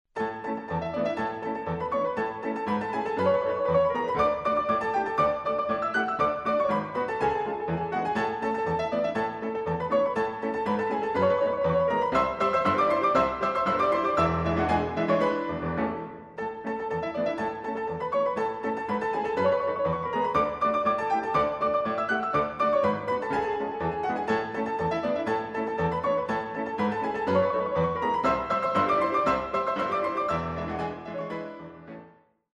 A komolyzene kedvelőinek is jut a fa alá lemez